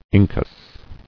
[in·cus]